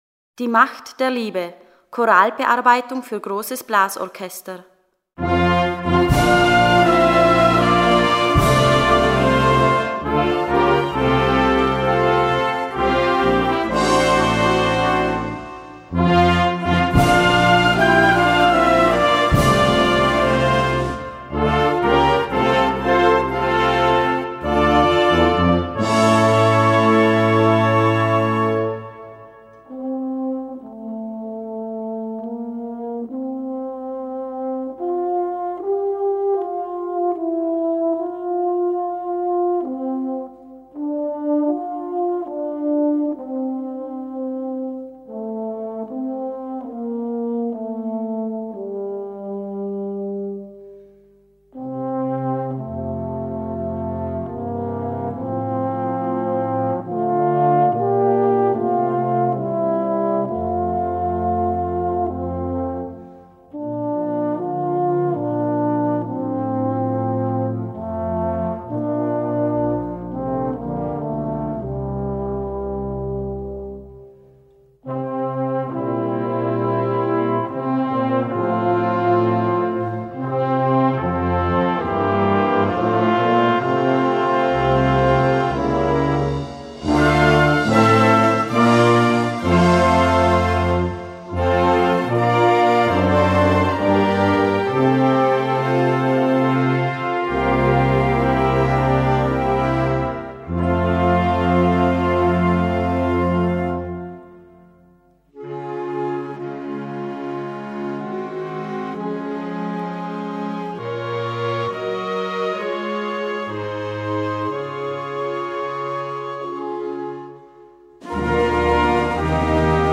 Gattung: Choralbearbeitung
Besetzung: Blasorchester